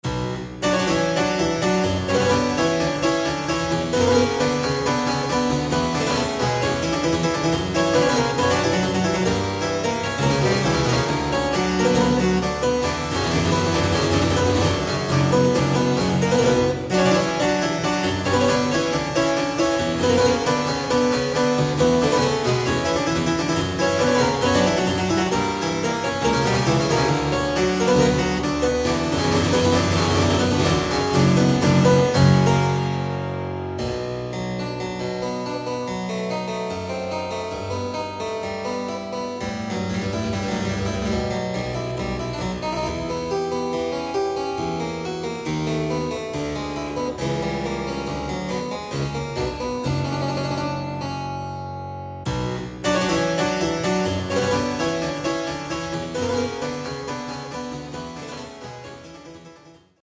A collection of old and new music for Harpsichord.